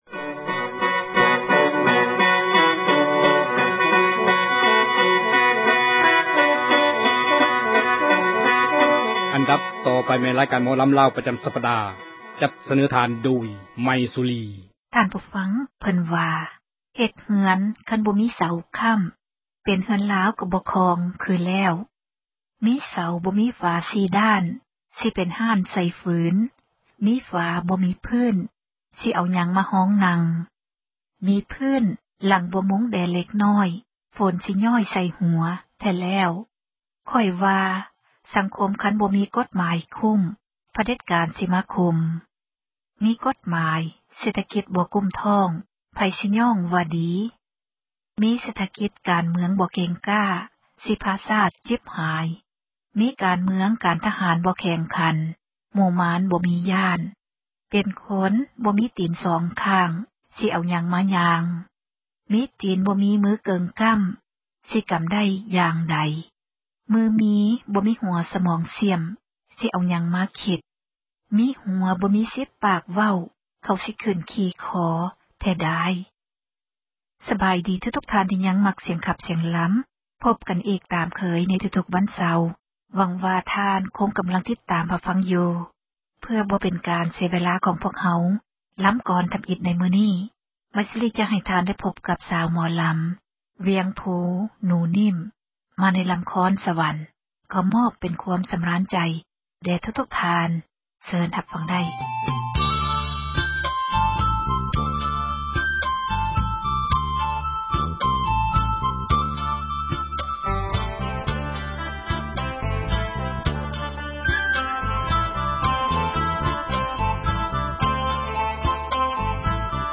ຣາຍການ ໝໍລຳ ລາວ ປະຈຳ ສັປດາ.